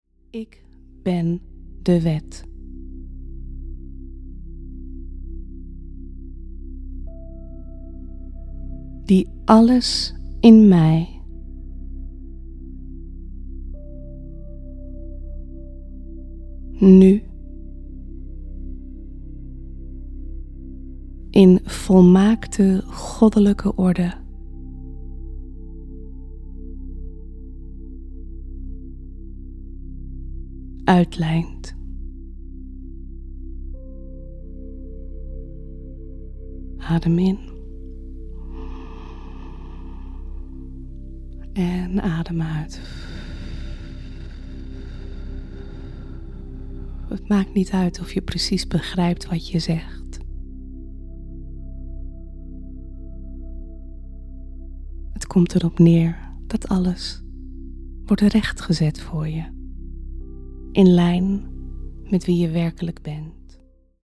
Meditatie “Blijven staan in jezelf”